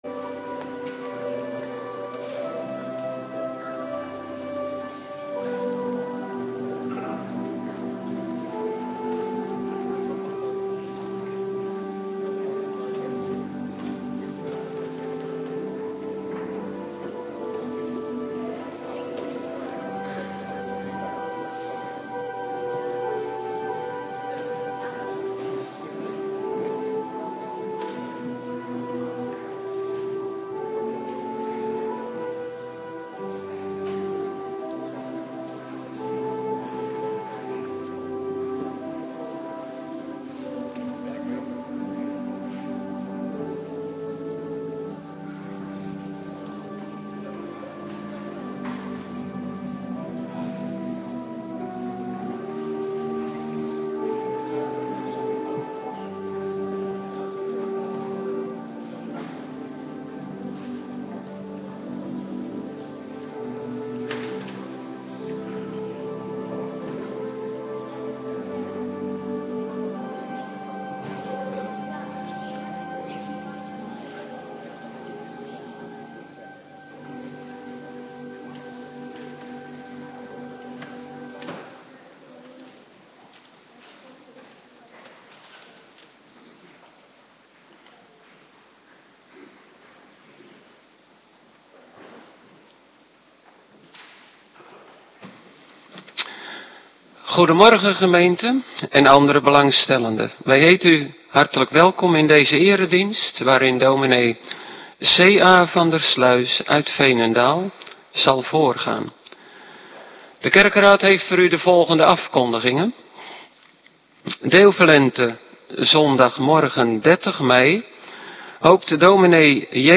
Morgendienst Eerste Pinksterdag - Cluster 3
Locatie: Hervormde Gemeente Waarder